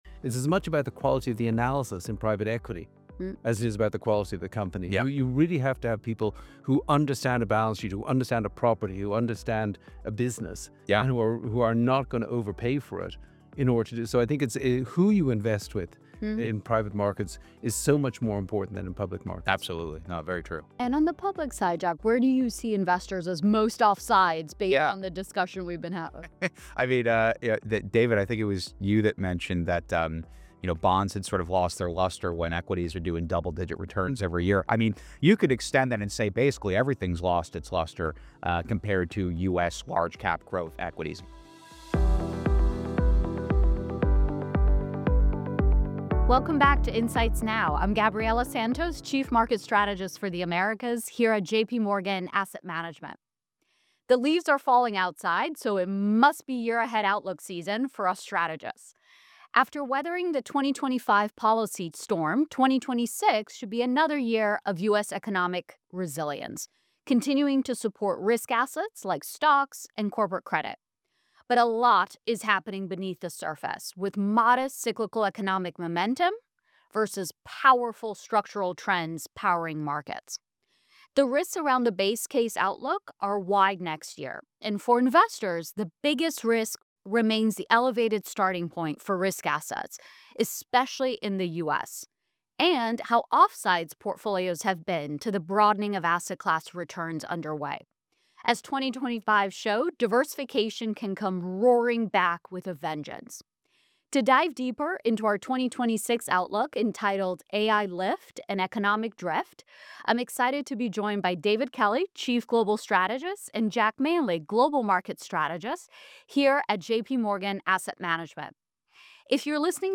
This engaging discussion unpacks how resilient U.S. economic growth is set against a backdrop of powerful structural trends, including the transformative impact of artificial intelligence.